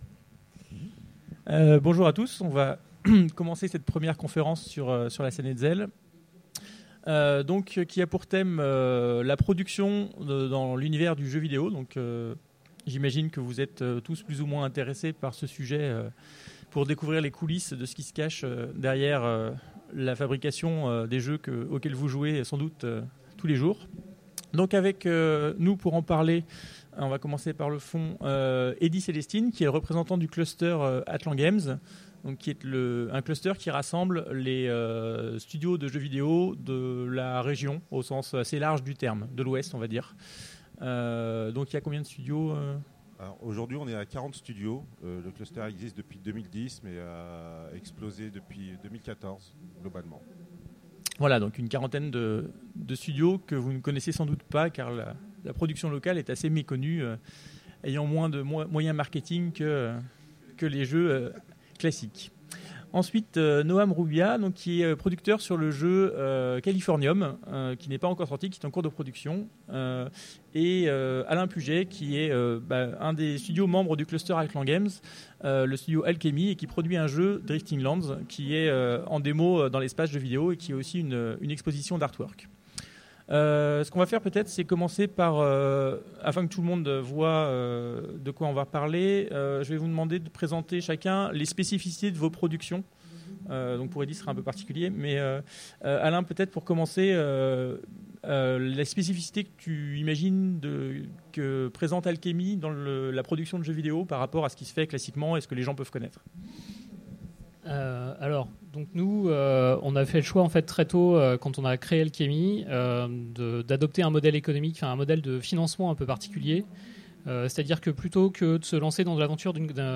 Utopiales 2015 : Conférence Les coulisses de la production de jeux vidéo